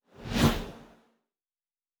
Fly By 01_2.wav